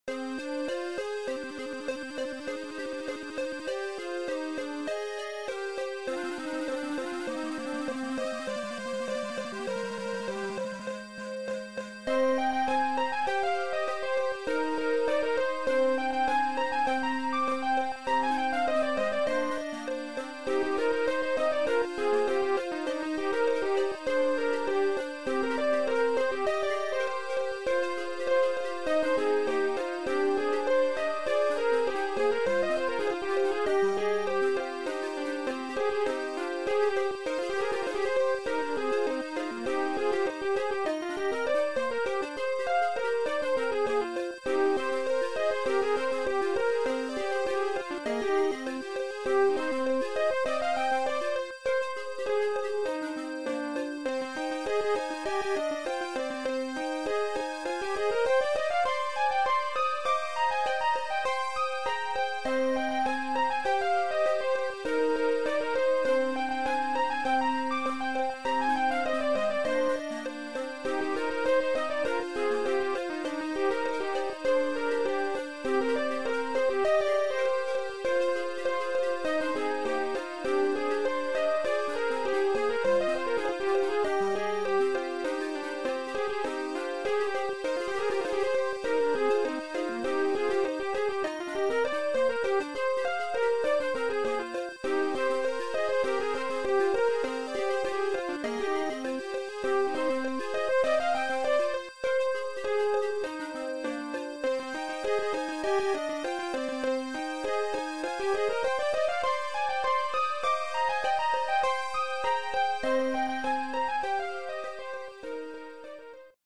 Opening and looping level beep boop music for a video game.